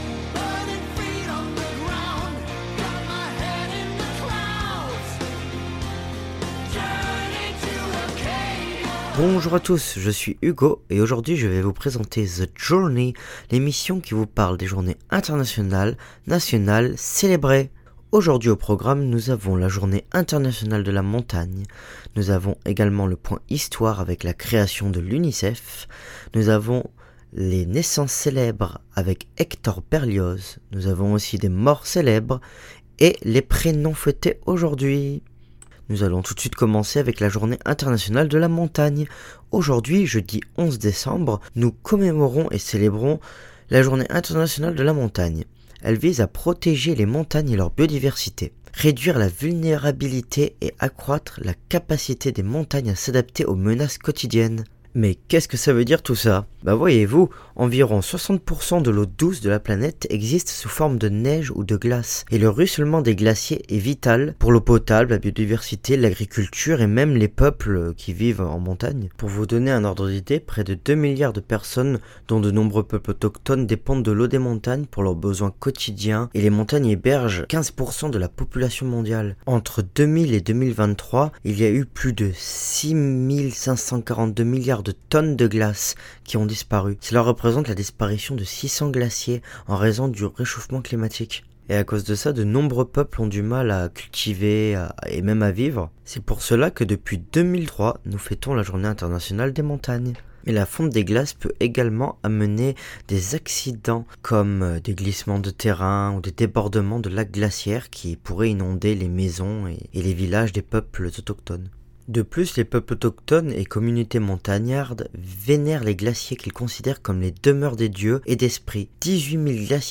Habillage musical :